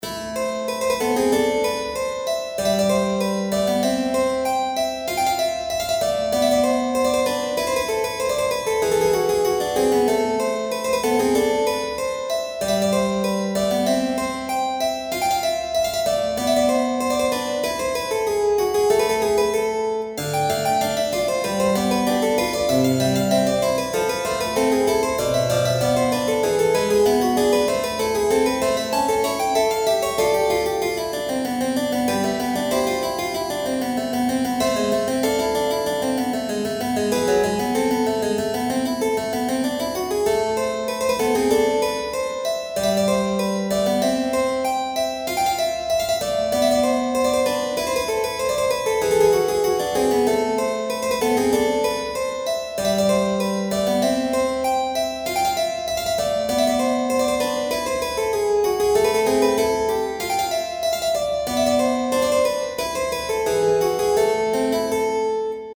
ファンタジー系フリーBGM｜ゲーム・動画・TRPGなどに！
星の冴えるほどの寒い夜。機械仕掛けの人形とかが踊ったりしているような。